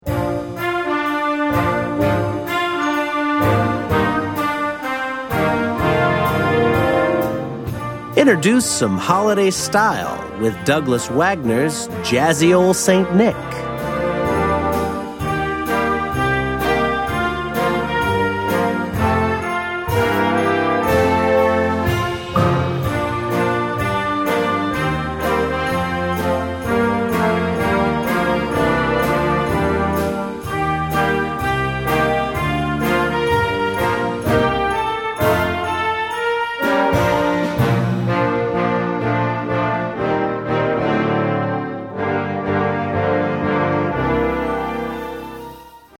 Gattung: Werk für Jugendblasorchester
Besetzung: Blasorchester